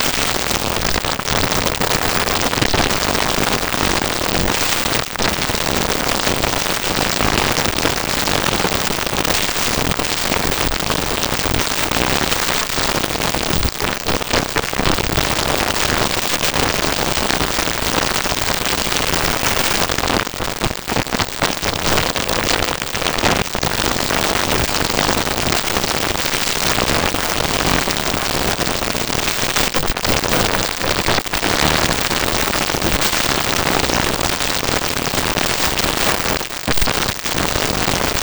PRÓBKA GŁOSU PL
probka-glosu-pl.wav